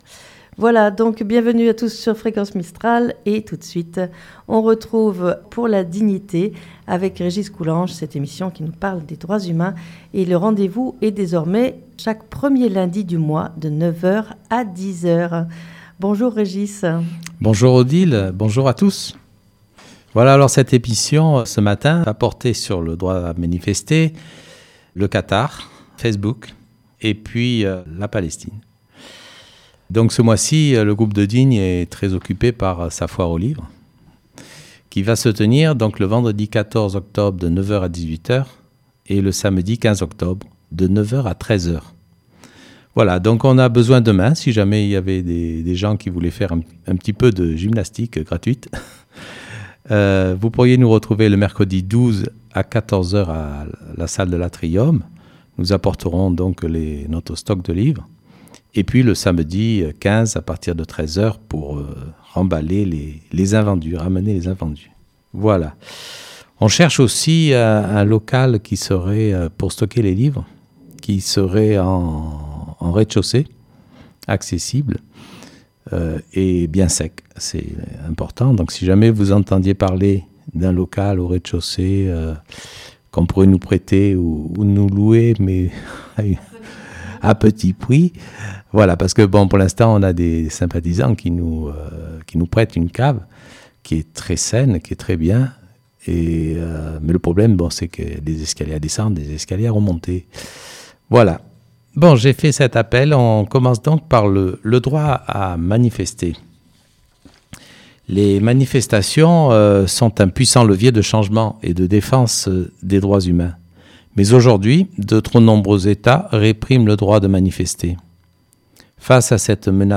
Beautiful cover of "Bella Ciao" in Persian by an Iranian woman